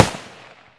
ak47_dist.wav